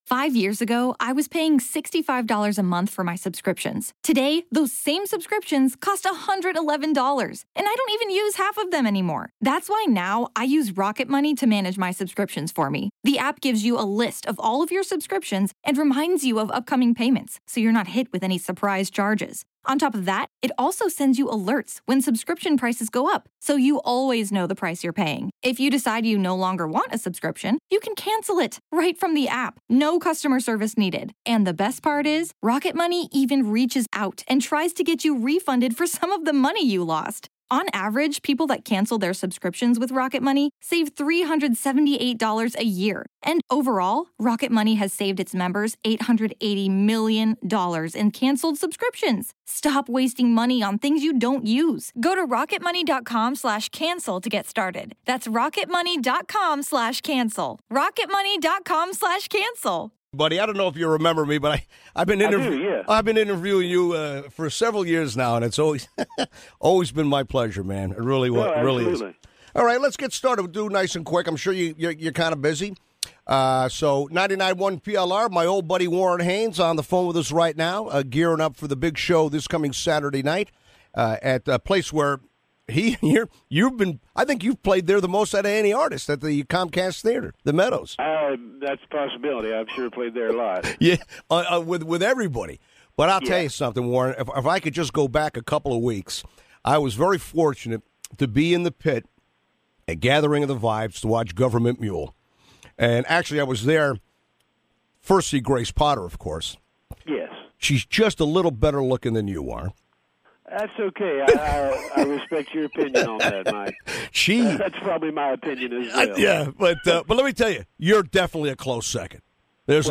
Warren Haynes Interview